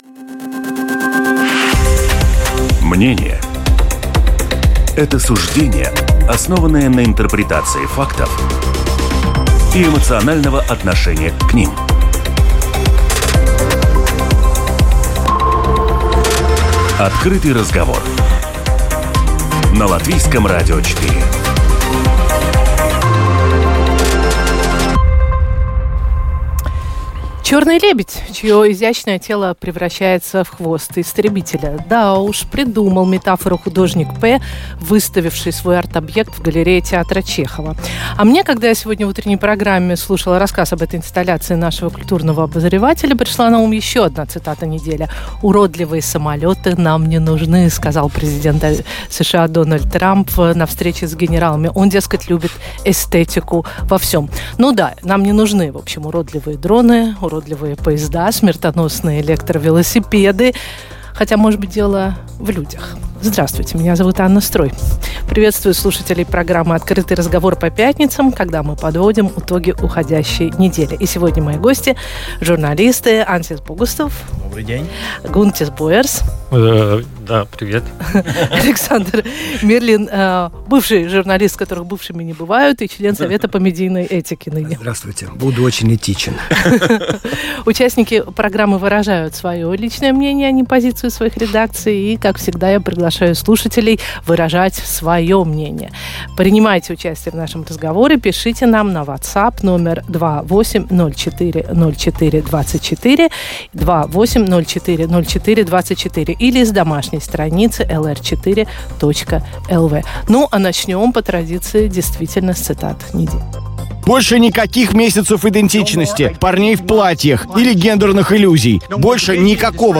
Cтамбульская конвенция как яблоко раздора, "шатдауном не пробовали?", "стена против дронов", мир для Газы, выборы в Молдове и Чехии, смерть на ж/д переезде… Все это обсудим в эфире итоговой программы “Открытый разговор”. Гости студии - журналисты